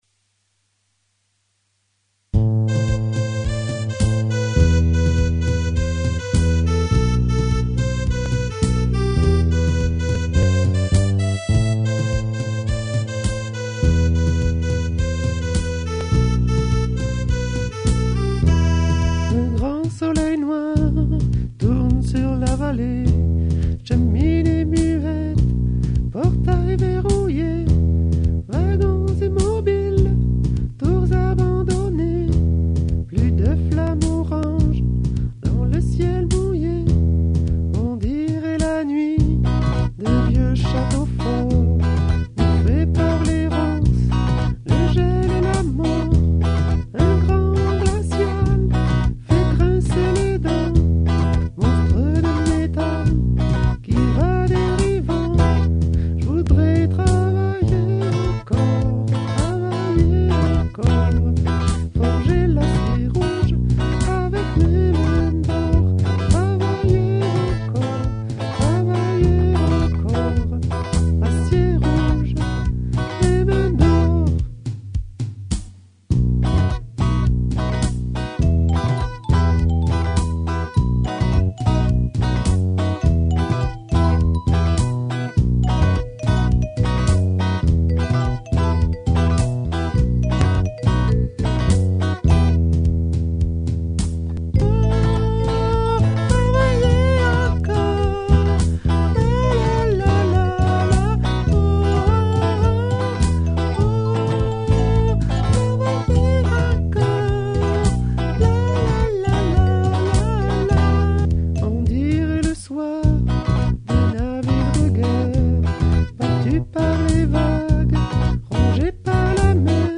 Alti
les-mains-dor-alti.mp3